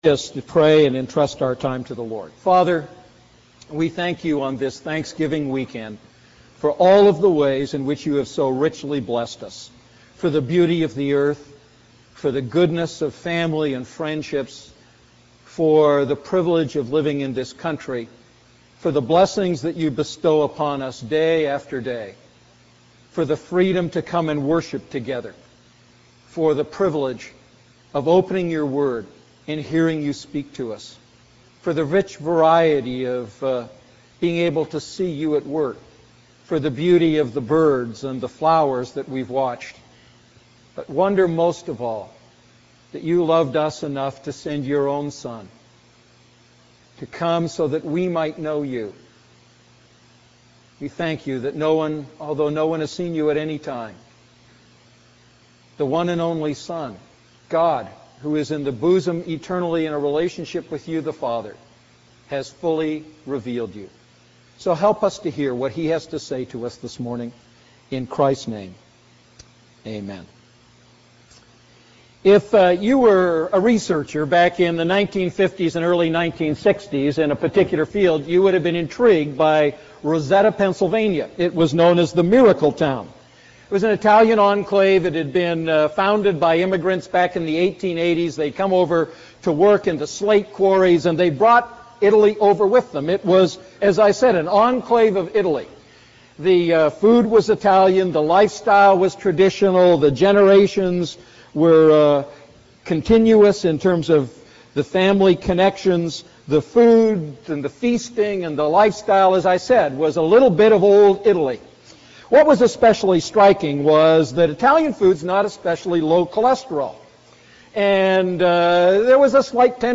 A message from the series "Luke Series II."